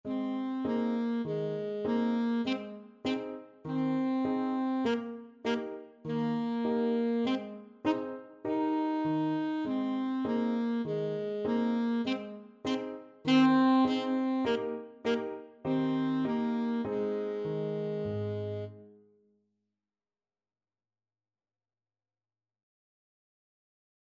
Alto Saxophone
4/4 (View more 4/4 Music)
Ab4-Eb5
Allegretto
Beginners Level: Recommended for Beginners